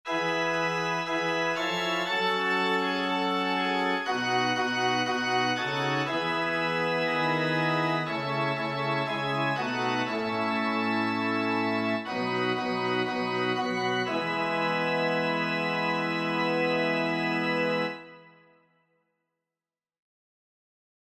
Chants de Prière universelle Téléchargé par